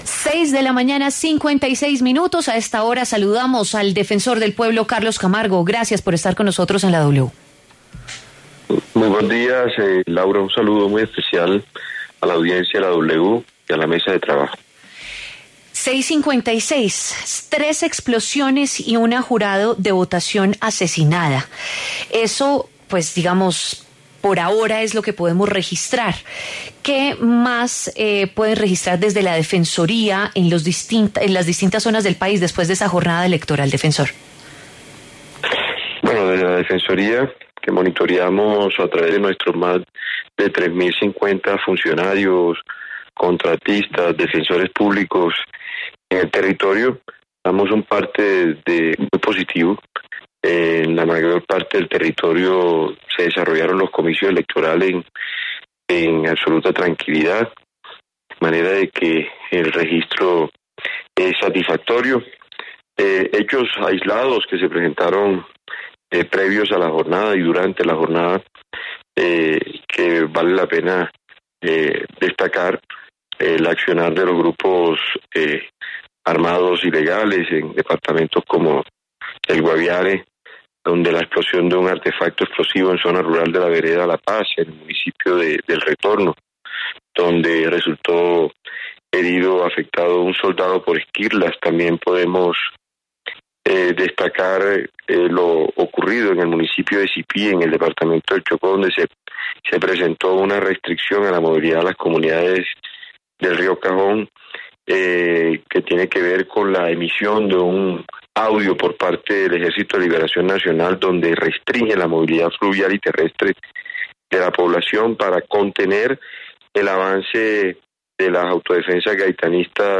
Carlos Camargo, defensor del Pueblo, habló en La W sobre la situación de orden público en el país durante las elecciones del 29 de mayo.
En el encabezado escuche la entrevista completa con Carlos Camargo, defensor del Pueblo.